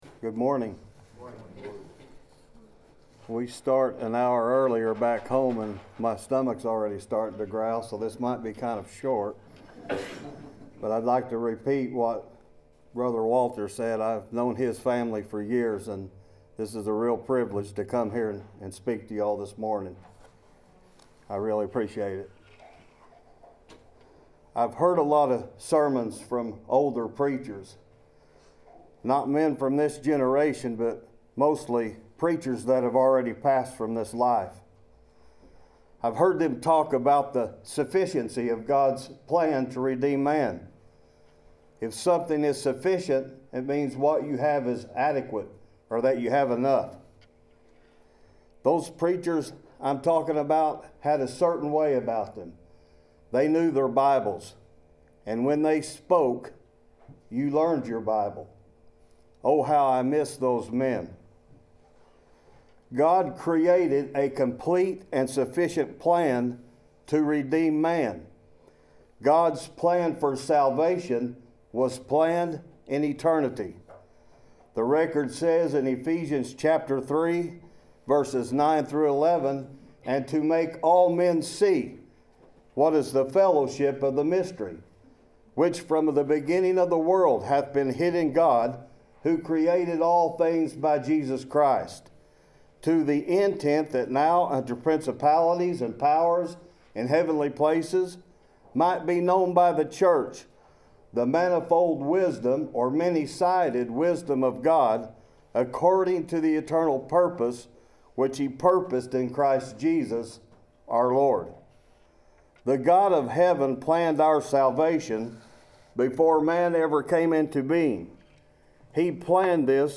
Service Type: Sunday 11:00 AM